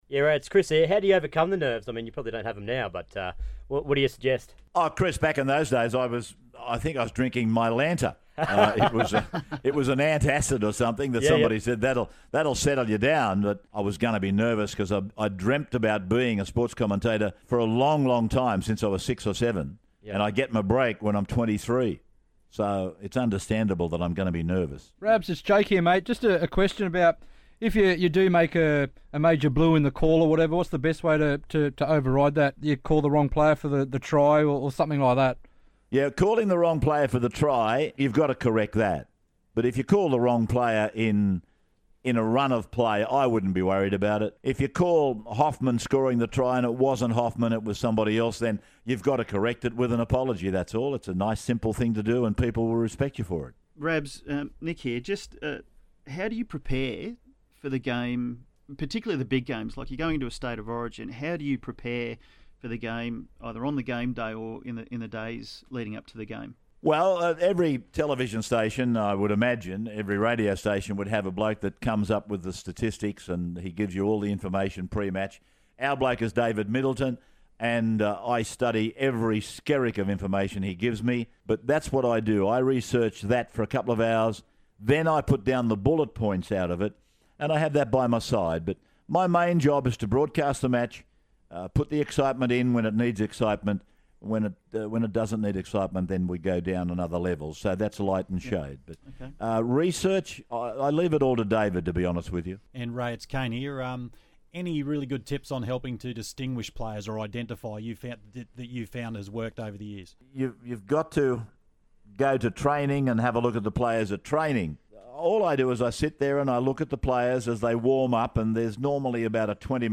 Triple M Listener Call Team catch up with Rabs (Ray Warren)